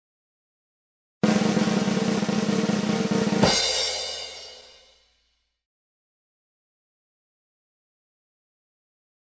Short Drum Roll Sound Effect Free Download
Short Drum Roll